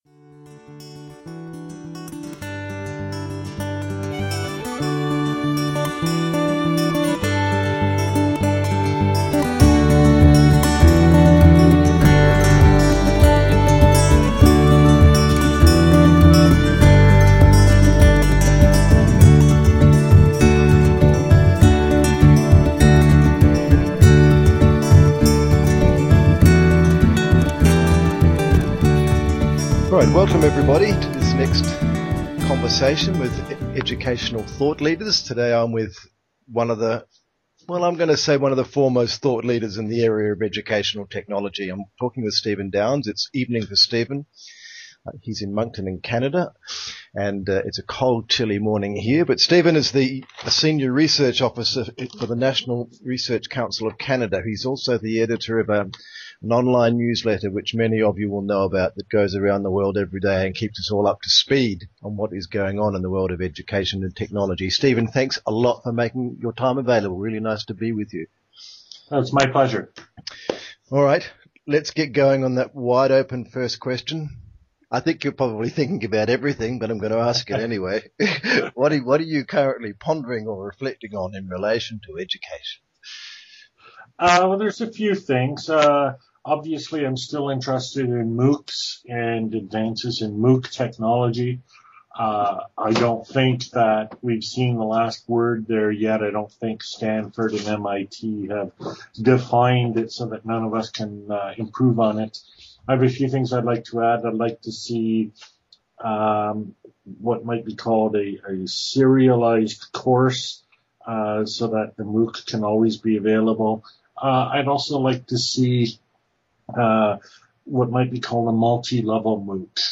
MOOCs Interview
Interview in which I talk a lot about what I see in the future for MOOCs - serialized MOOCs, social community, etc. I also discuss personal learning environments and describe how they fit in to MOOCs.